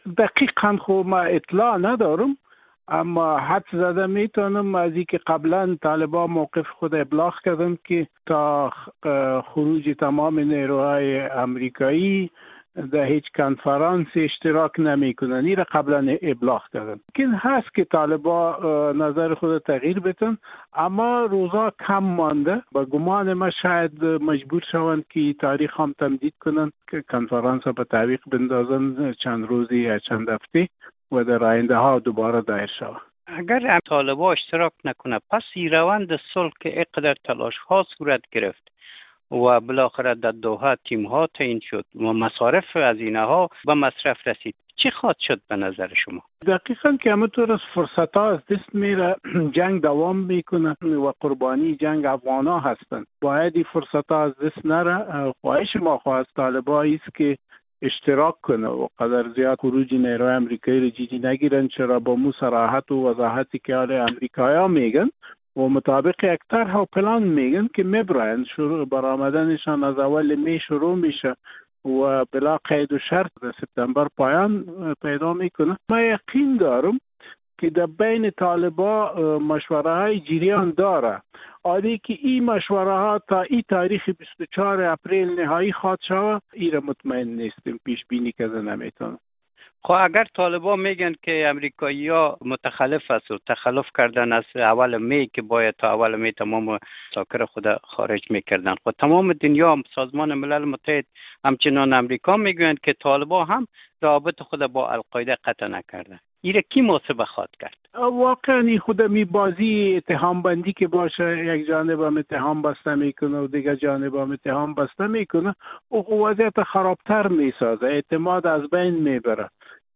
محمد عمر داوودزی مشاور ارشد رئیس جمهور برای اجماع منطقه‌ای شام دیروز (۳۰ حمل) در مصاحبهٔ با رادیو آزادی گفت، بهتر است که طالبان به صدای افغان‌ها و جامعه جهانی گوش دهند.
گفتگو با محمد عمر داوودزی